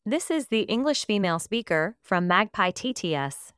🔉EN-US.Female.Female-1
EN-US.Female.Female-1_MagpieTTS.wav